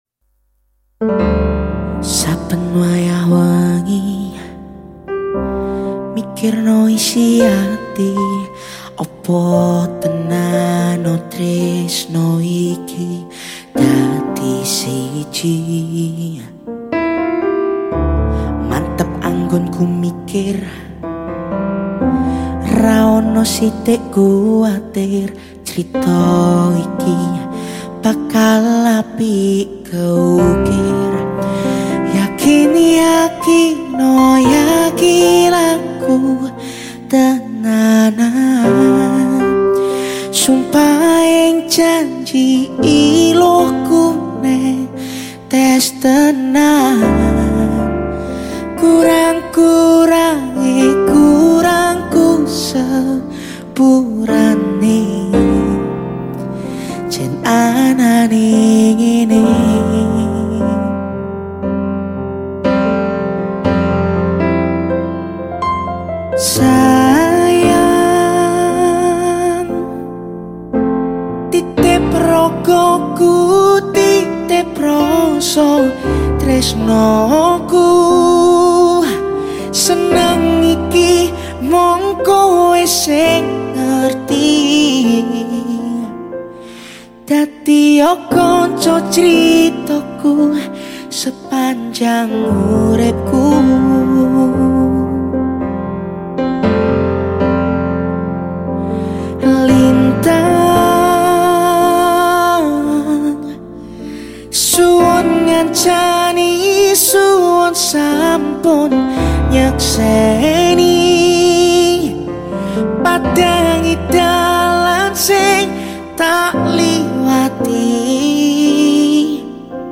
cover by me🔥nek rame tak cover full band